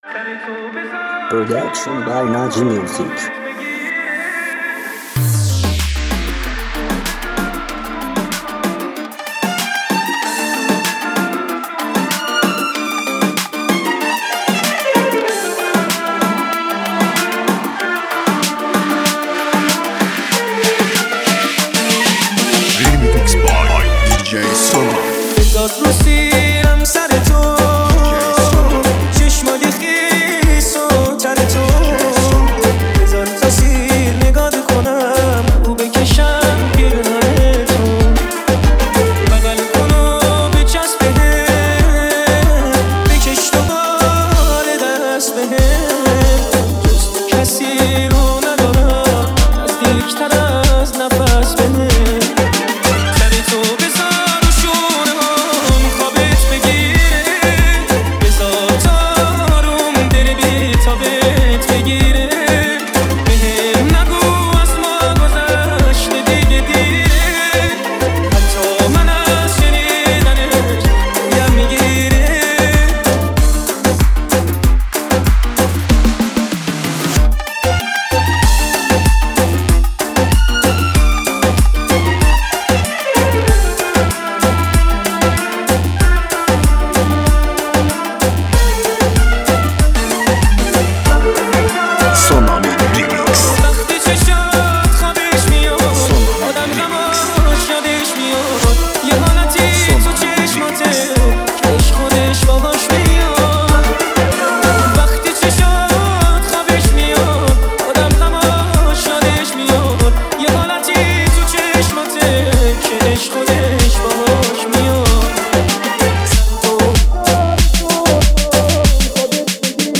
آهنگ جدید آهنگ های ایرانی ریمیکس